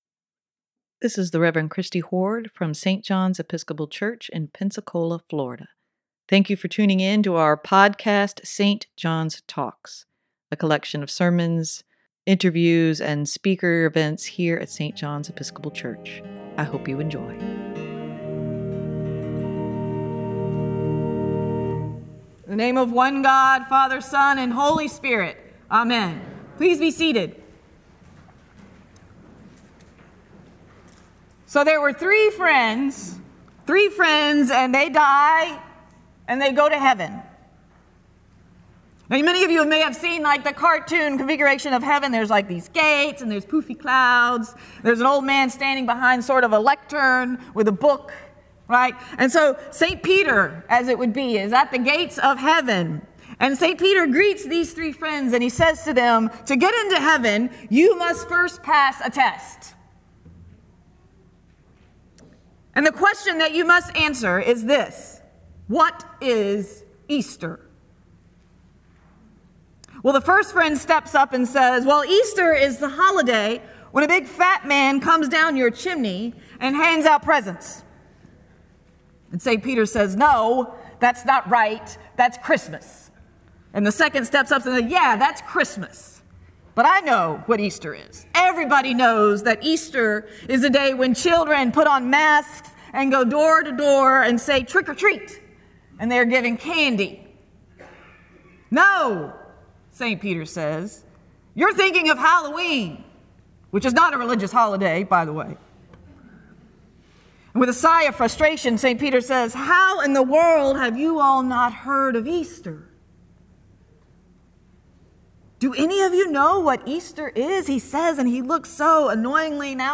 Sermon for Easter Sunday, March 31, 2024: Life is better with Jesus